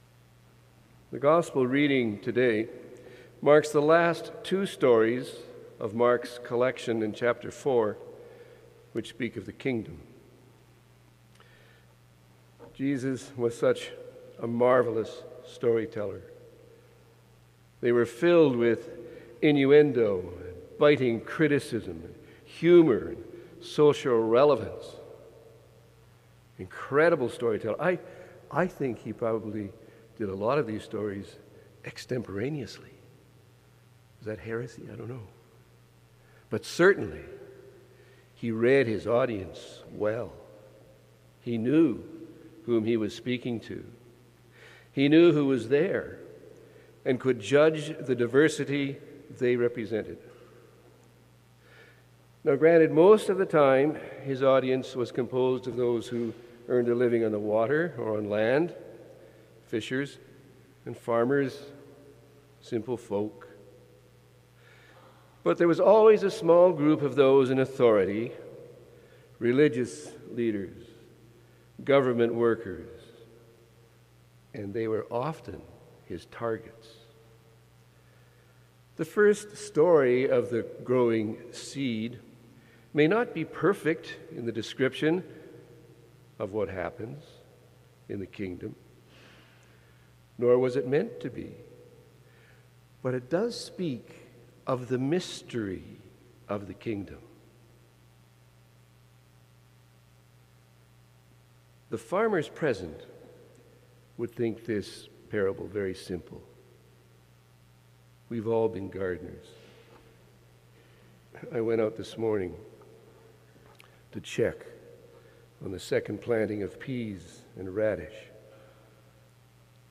Sermon: 11.00 a.m. service